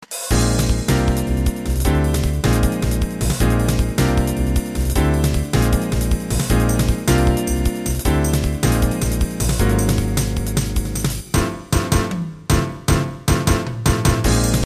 Roland Virtual Sound Canvas　VSC-88（ソフト）[MP3ファイル]
Microsoft GS Wavetable SW Synthに比べると音は多少良い。私的には迫力があってよいと思う。
リバーブ、コーラス、ディレイ、TVF